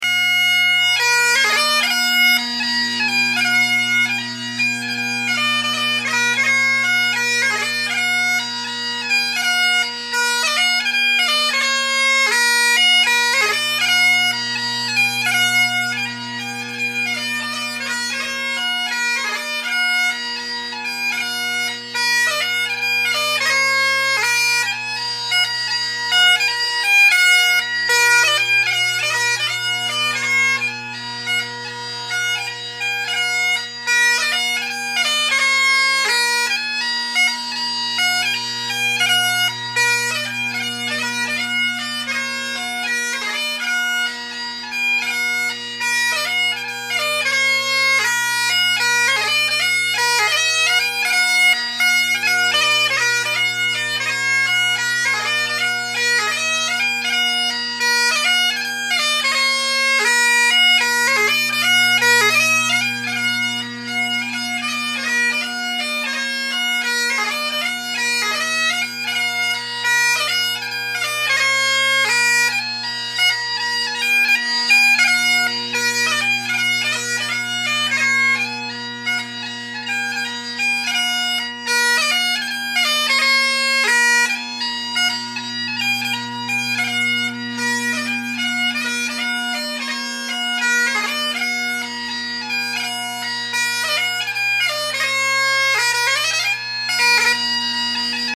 The top hand F, high G, and high A all sound very good. D is spot on, as is B. The other notes are a given.
I personally think the Colin Kyo sounds the best!